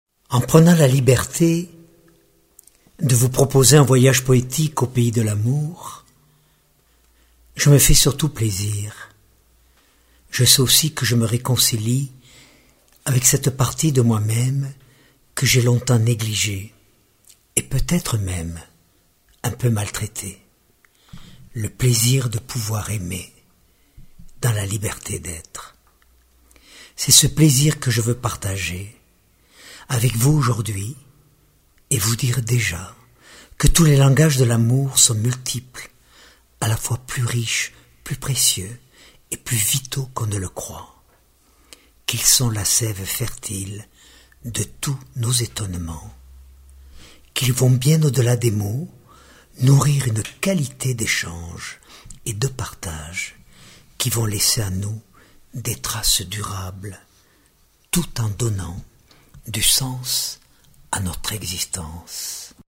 Diffusion distribution ebook et livre audio - Catalogue livres numériques
maître de flûte arménienne
piano
Que nous soyons engagés ou pas dans une relation d’amour, chacun d’entre nous sera sensible à la voix d’un homme qui a su aimer et être aimé, à pleine vie.